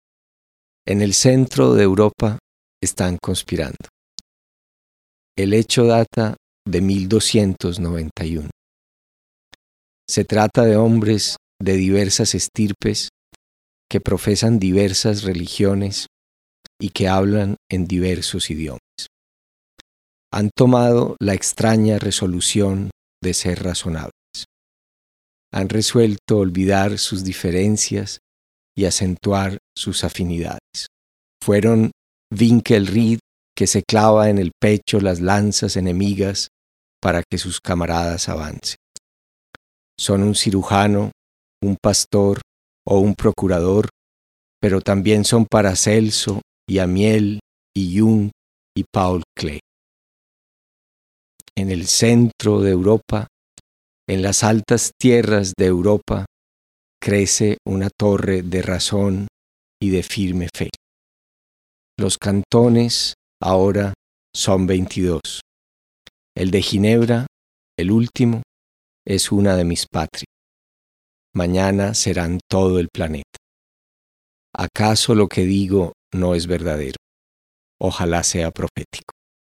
Suiza en la poesía de Borges y en la voz de Héctor Abad.